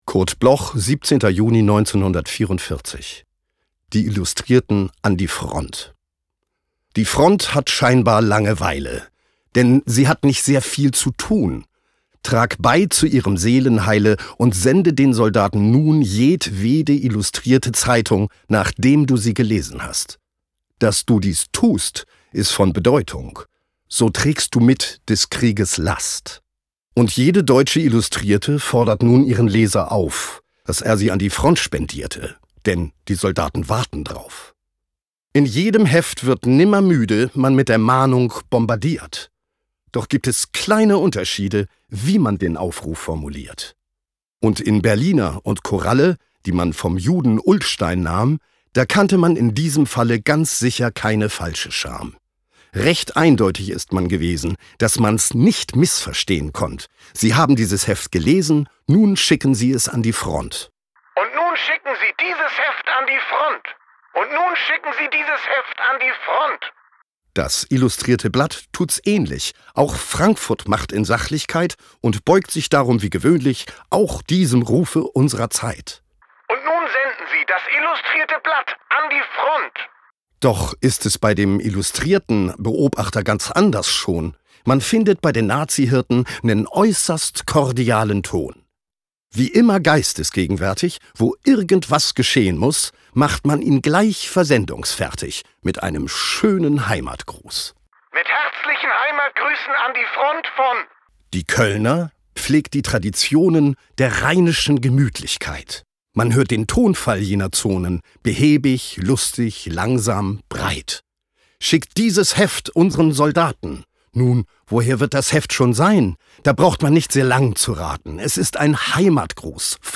Recording: The Soundshack, Hamburg · Editing: Kristen & Schmidt, Wiesbaden